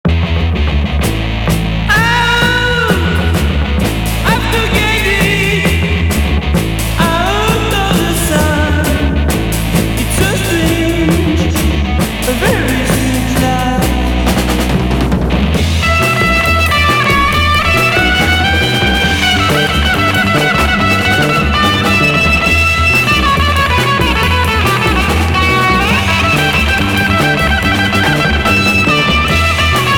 Heavy rock psychédélique Premier 45t retour à l'accueil